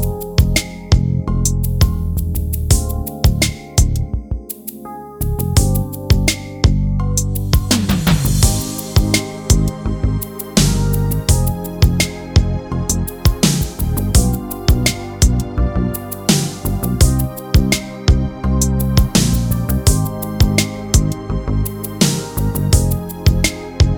no Backing Vocals R'n'B / Hip Hop 5:50 Buy £1.50